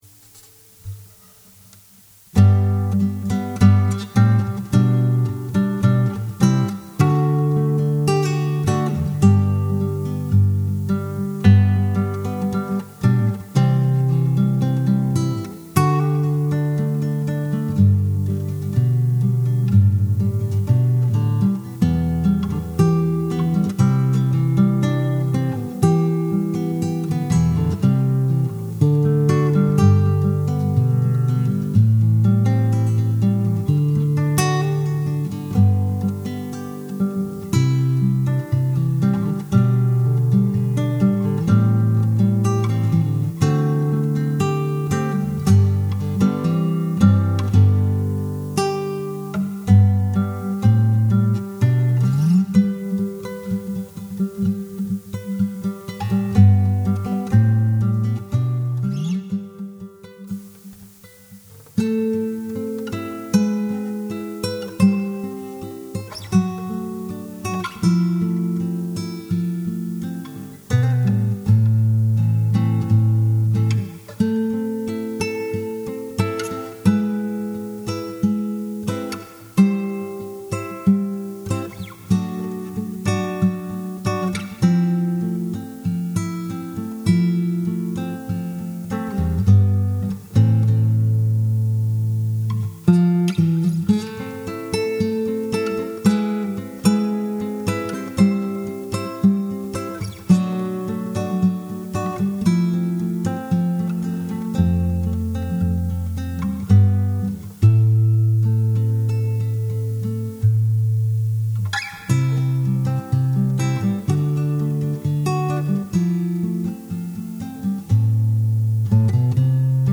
The music helps to create a relaxing and welcoming ambience.